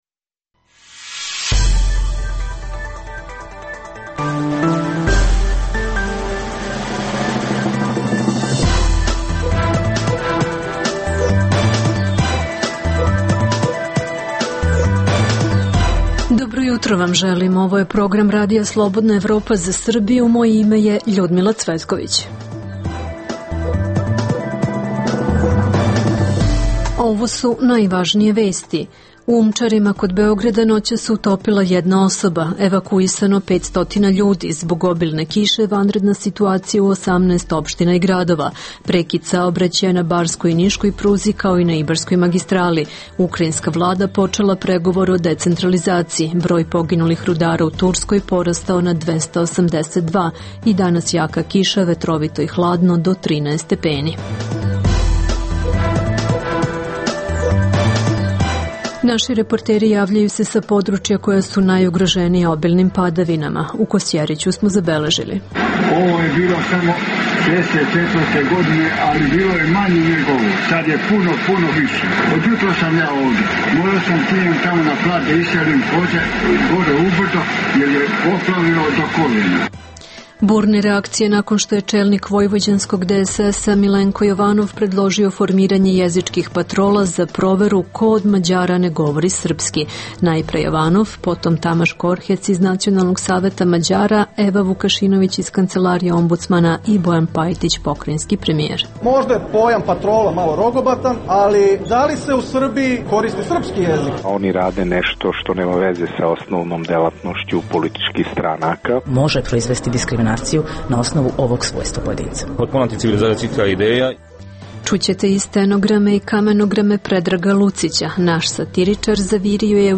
Zbog obilne kiše vanredna situacija u 18 opština i gradova. Naši reporteri javljaju se sa područja koja su najugorženija obilnim padavinama.